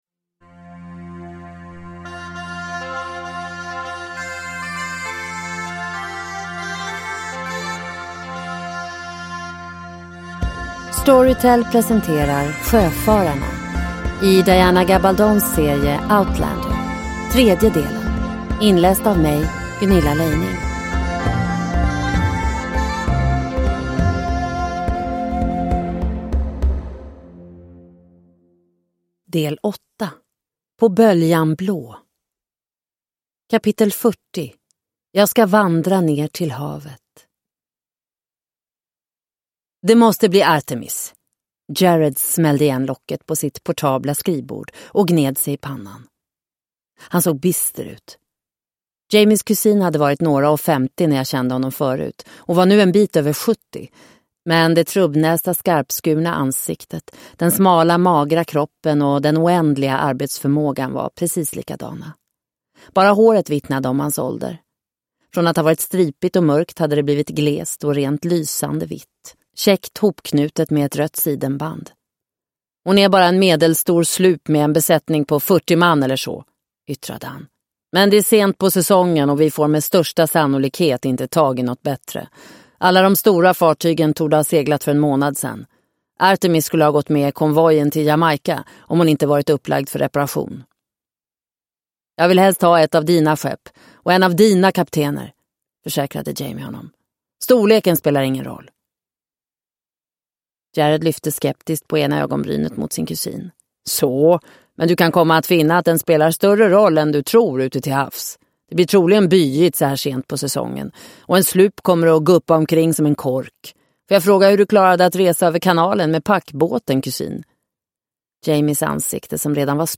Sjöfararna - del 3 – Ljudbok – Laddas ner